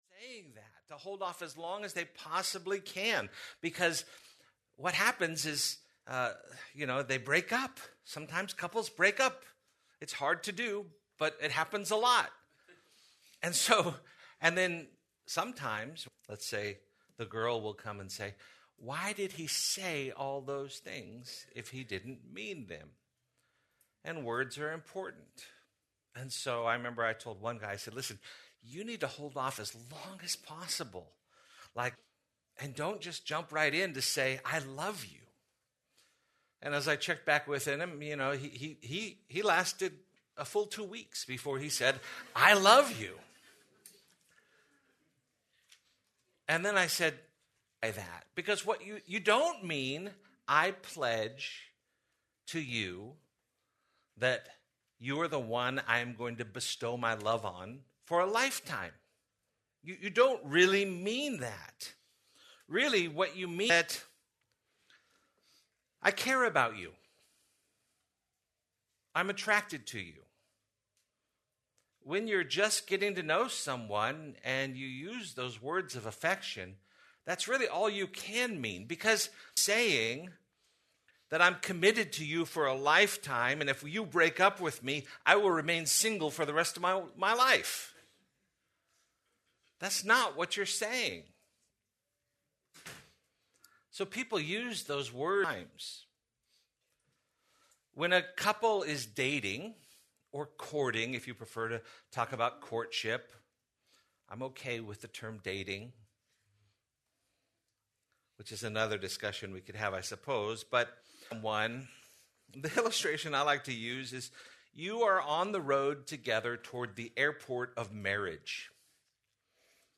Please note that, due to technical difficulties, this recording skips brief portions of audio.